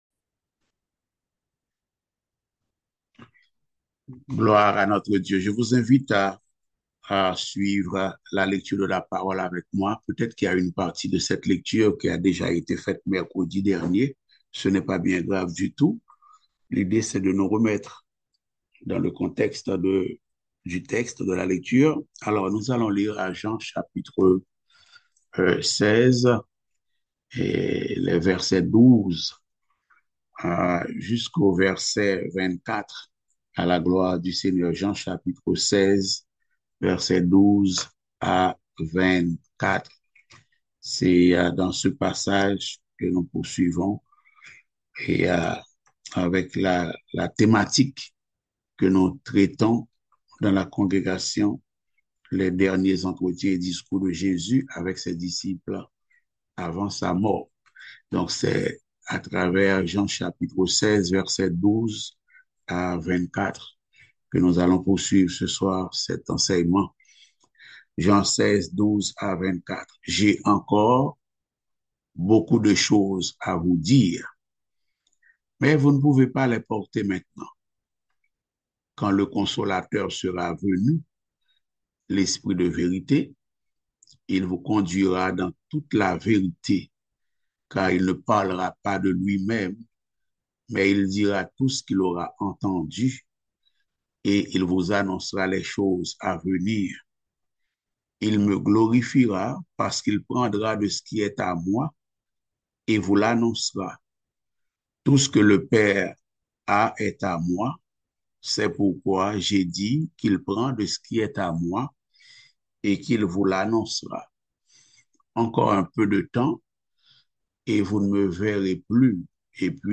Passage: Jean 16.13-24 Type De Service: Études Bibliques « Courons avec persévérance dans la carrière qui nous est ouverte.